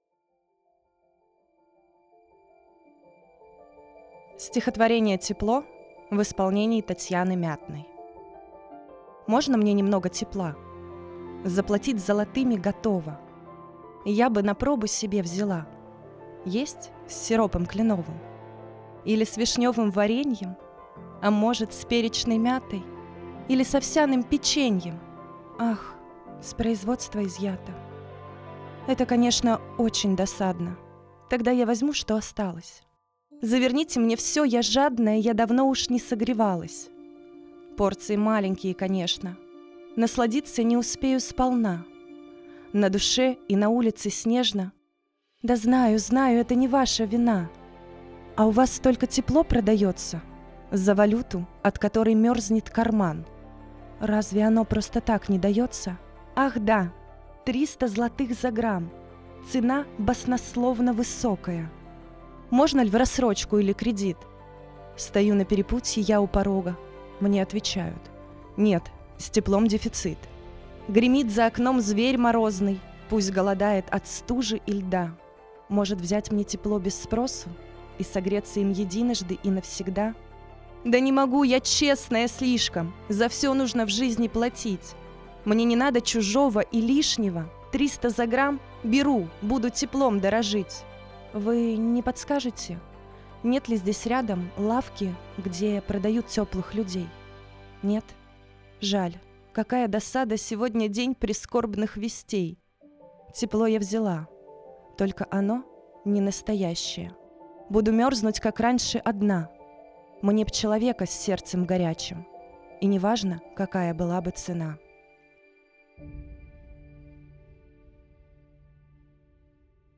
Каждый читатель сможет услышать в нём что-то родное и близкое, проникнуться теплотой слов и приятными голосами исполнителей.
Для вас читают: